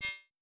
GenericNotification7.wav